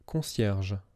A concierge (French pronunciation: [kɔ̃sjɛʁʒ]
Concierge_pronunciation.wav.mp3